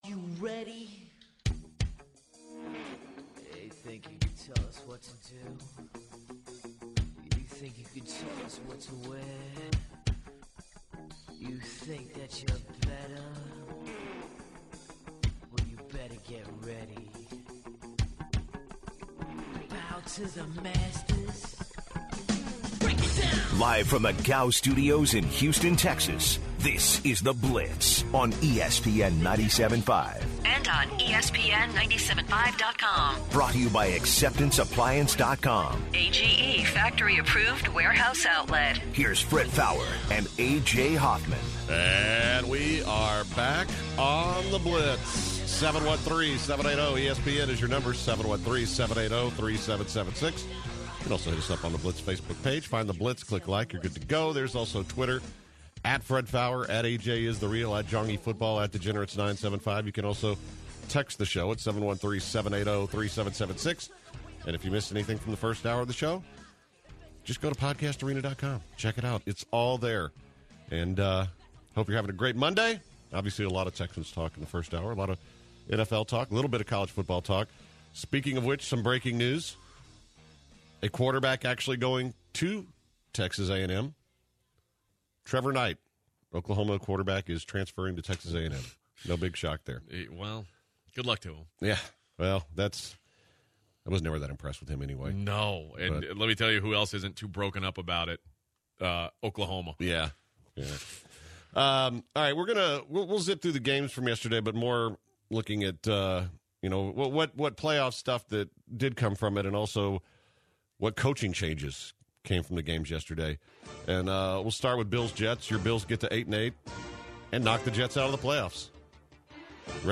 Stanford Routt came in studio to talk about the Texans making the playoffs and the UH bowl win. The hour came to close with the Gem of The Day.